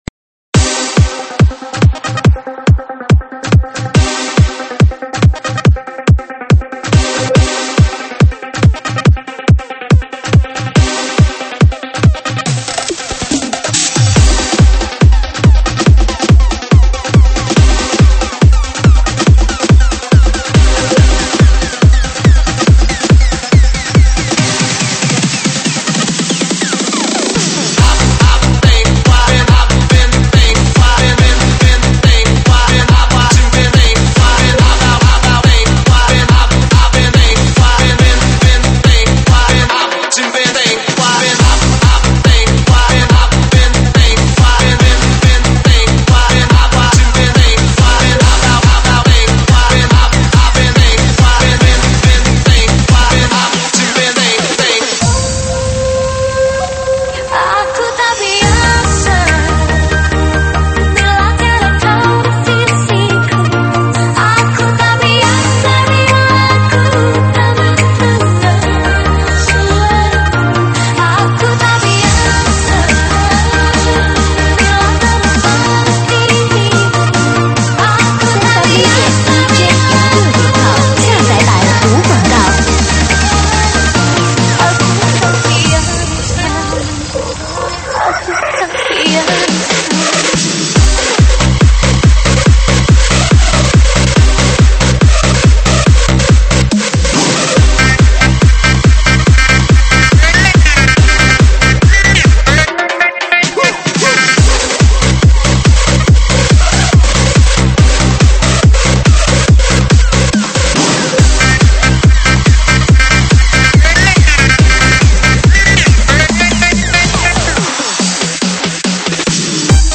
舞曲类别：英文舞曲